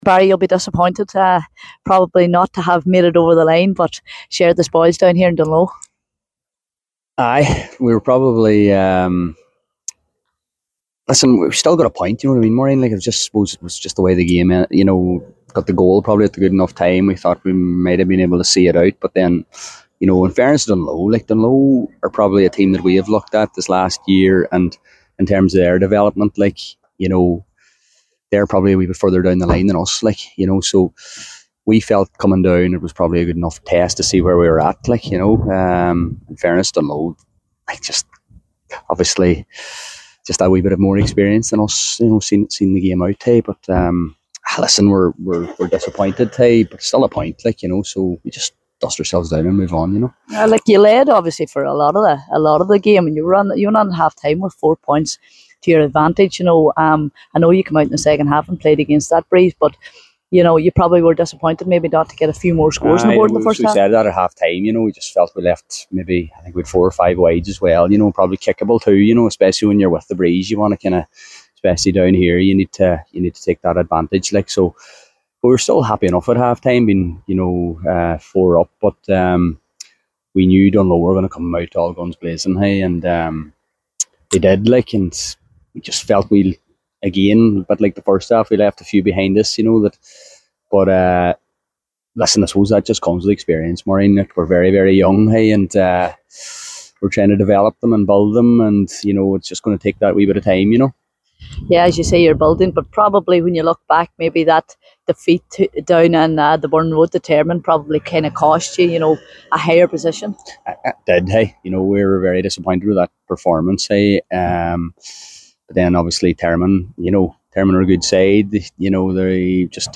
After the game